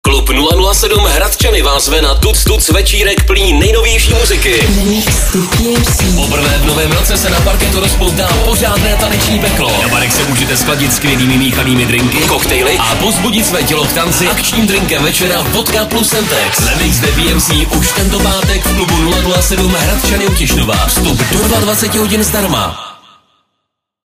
taneční párty